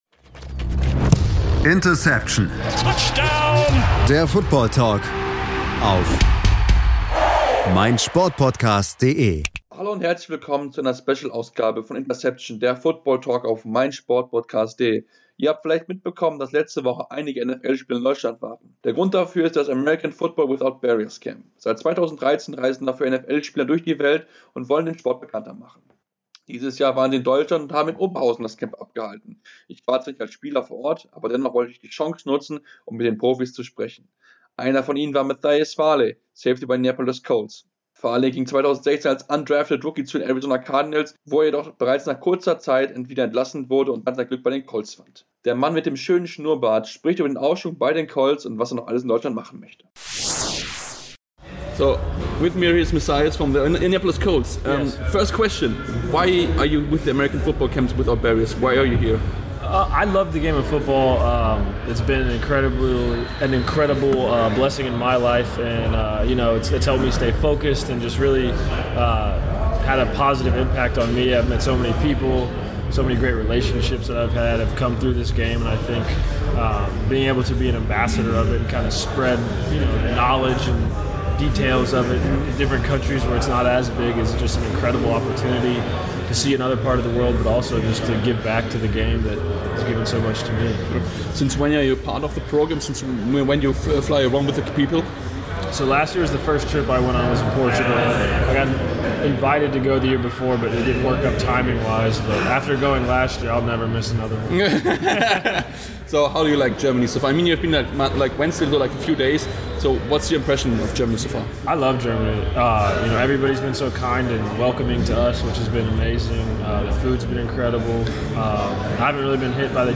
interview-matthias-farley.mp3